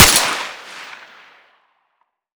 Index of /server/sound/weapons/cw_m14
fire_suppressed.wav